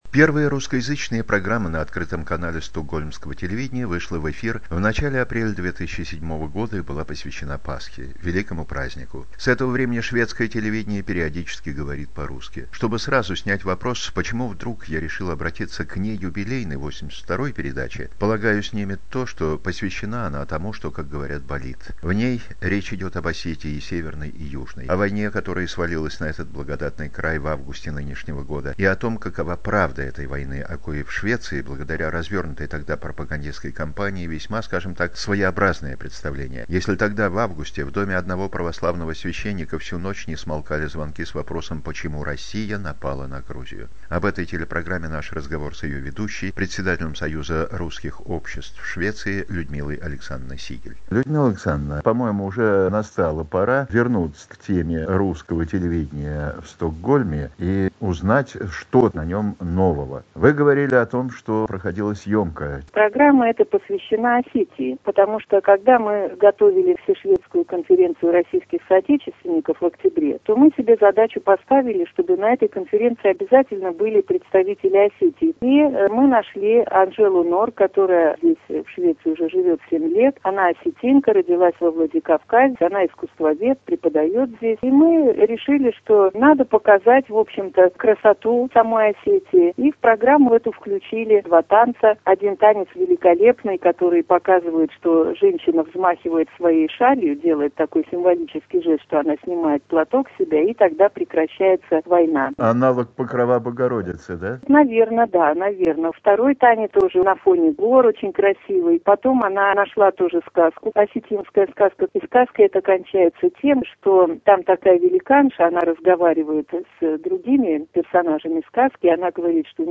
Интервью журналиста
Краткий репортаж и